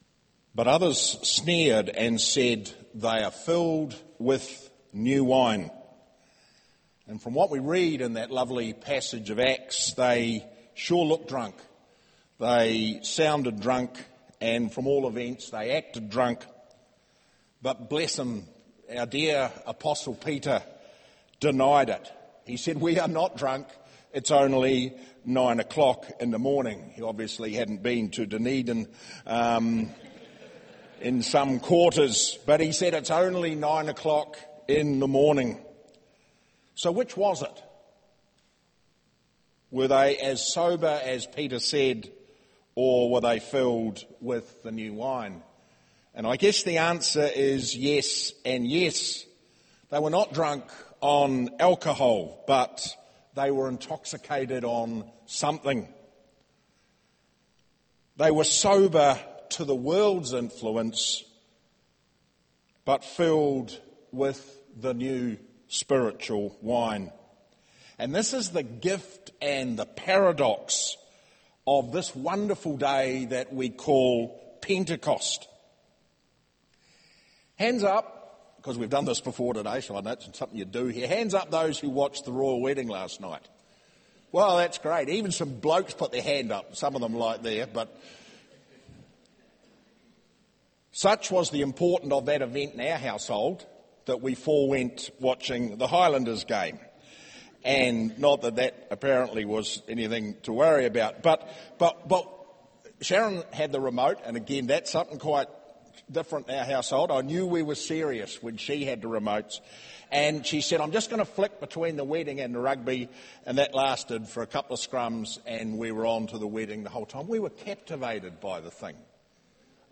Service Type: Holy Communion